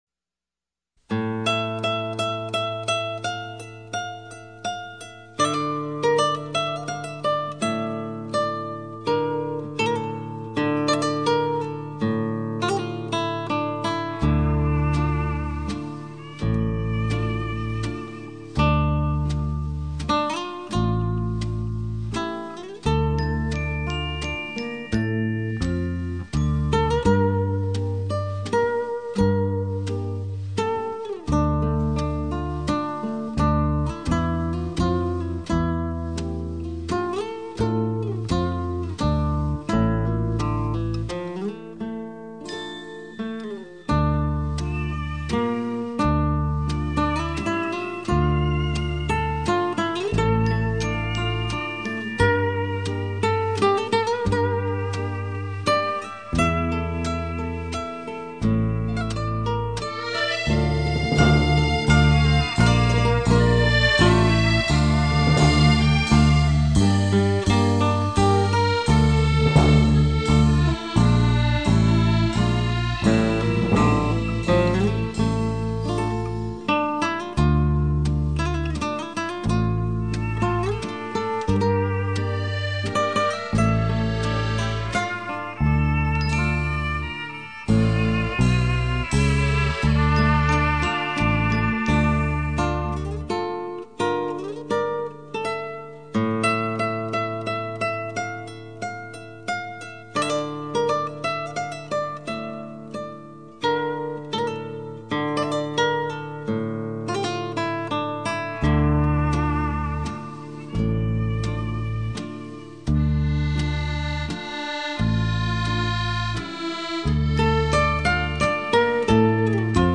弹奏风格晶莹剔透、 飘逸轻灵 ，绝不炫技而是恰如其分， 风格淳和不惊 。